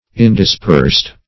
indispersed - definition of indispersed - synonyms, pronunciation, spelling from Free Dictionary
Indispersed \In`dis*persed"\, a.